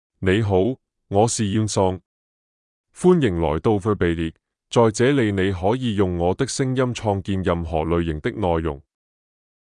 YunSong — Male Chinese AI voice
YunSong is a male AI voice for Chinese (Cantonese, Simplified).
Voice sample
Listen to YunSong's male Chinese voice.
Male